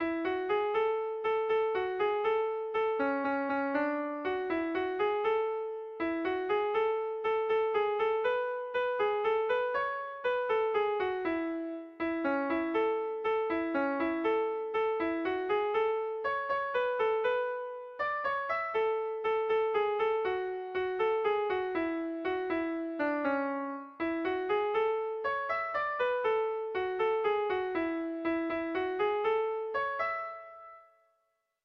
Erromantzea
Hamarreko handia (hg) / Bost puntuko handia (ip)
ABDEF